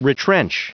Prononciation du mot retrench en anglais (fichier audio)
Prononciation du mot : retrench